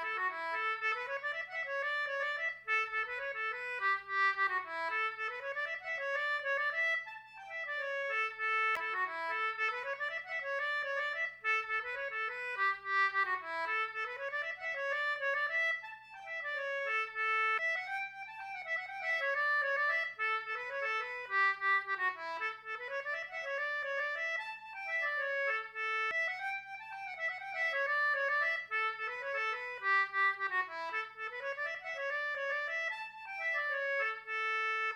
Celtic Button Box Playlist Samples
HORNPIPES
English concertina